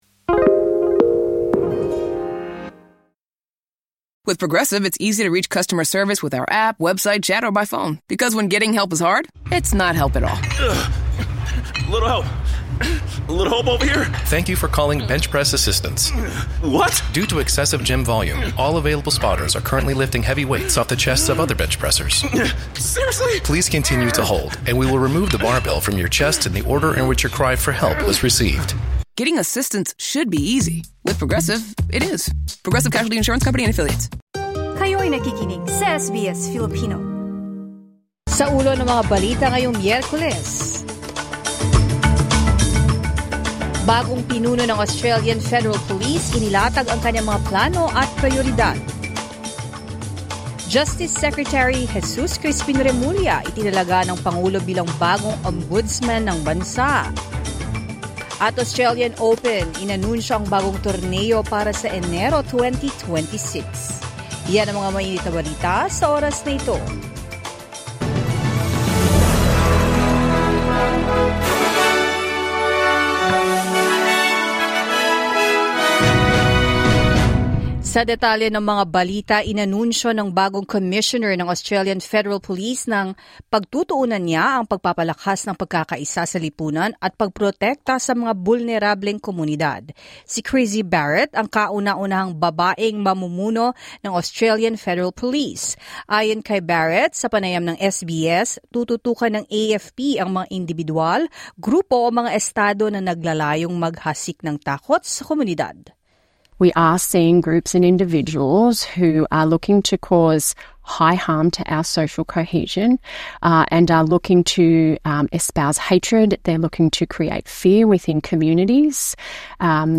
SBS News in Filipino